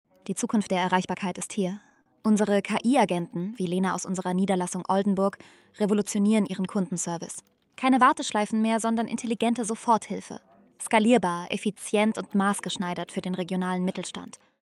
KI Telefonassistent
KI-Assistentin_Oldenburg.mp3